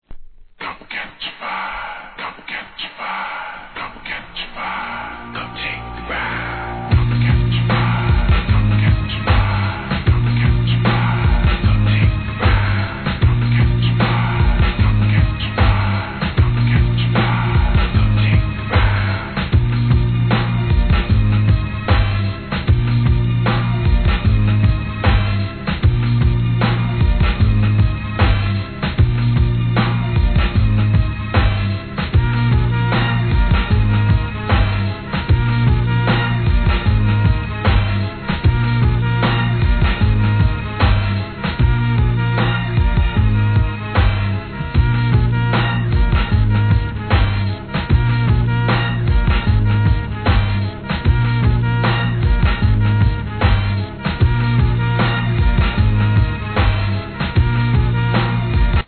ブレイク・ビーツ